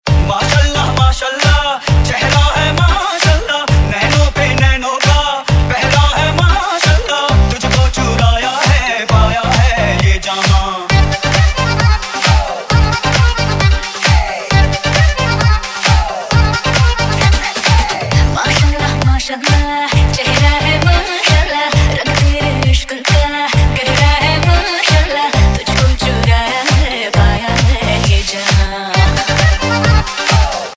File Type : Latest bollywood ringtone